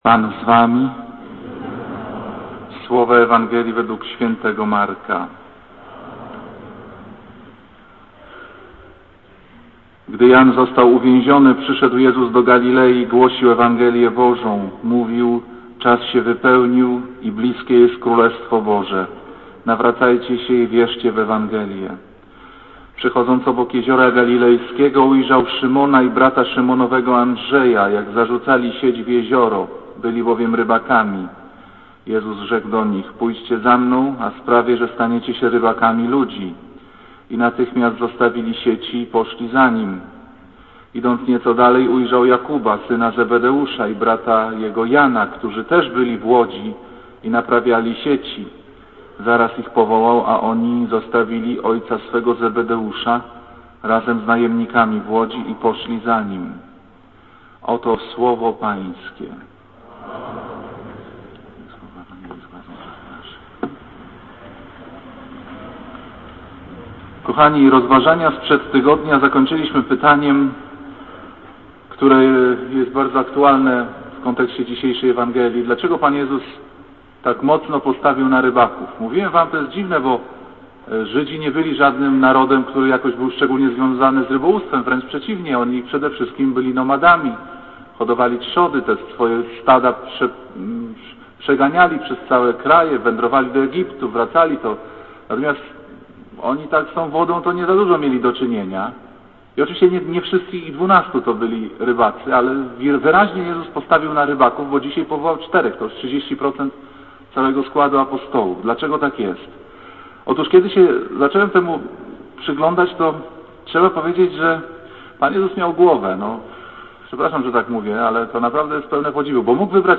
Kazanie z 22 stycznia 2006r.
niedziela, godzina 15:00, kościół św. Anny w Warszawie « Kazanie z 15 stycznia 2006r.